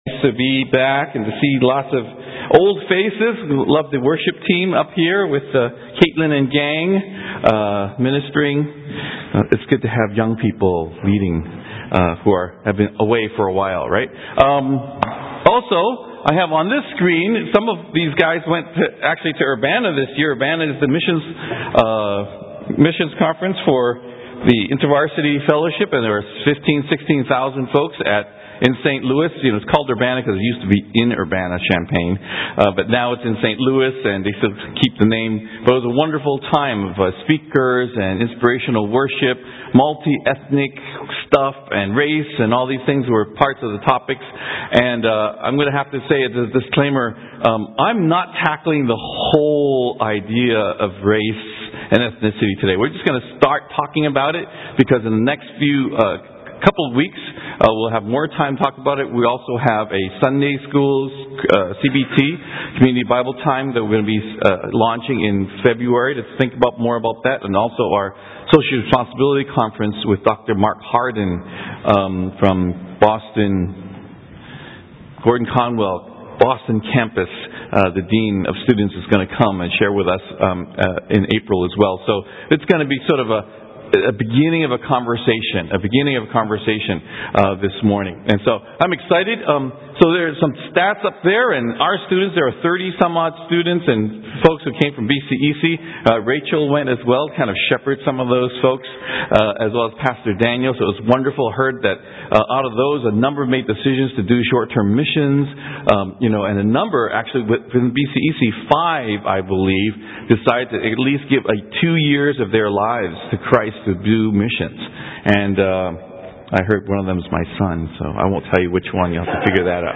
Tag: Recent Sermons - Page 116 of 178 | Boston Chinese Evangelical Church